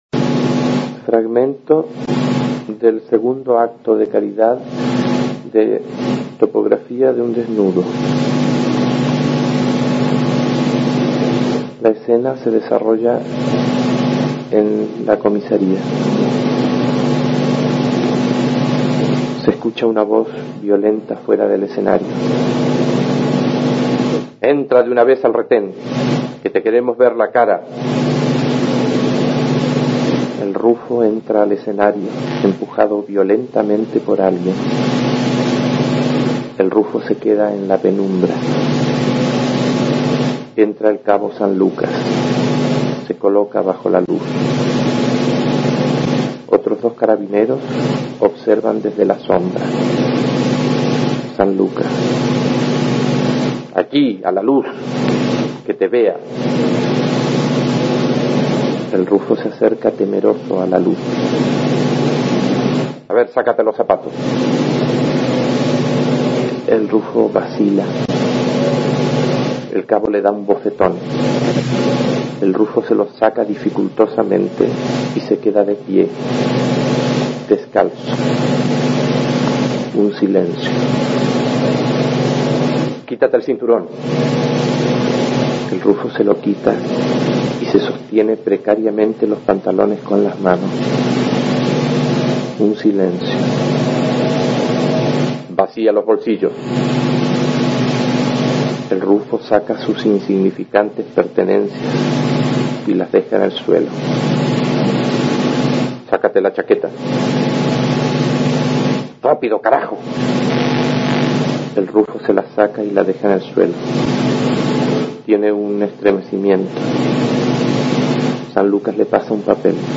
Te invitamos a escuchar al dramaturgo chileno Jorge Díaz leyendo un fragmento de su obra Topografía de un desnudo: esquema para una indagación inútil (1966).